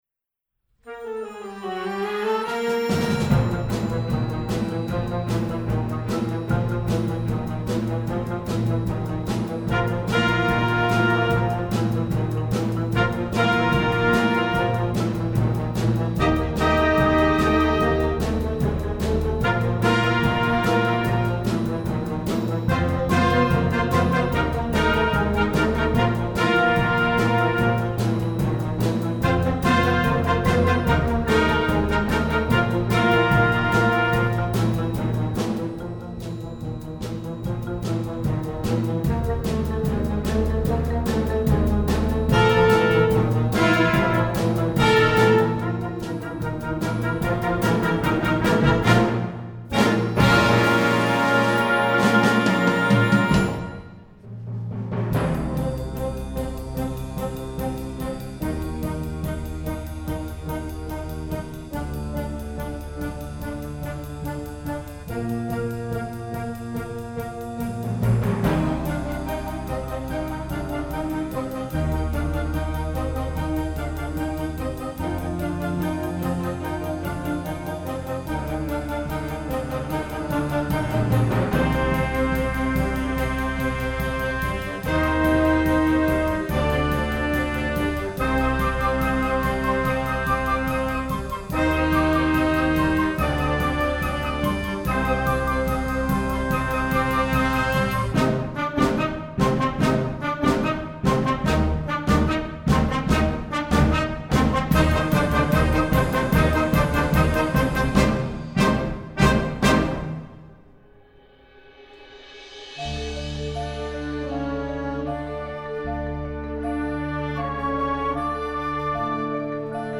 Instrumental Concert Band Movie/TV/Broadway
Concert Band